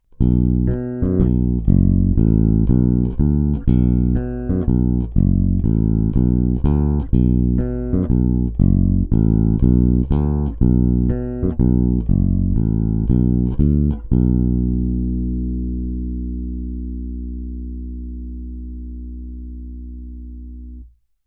I já jsem nahrál zvukové ukázky, ovšem se stávajícími snímači Bartolini.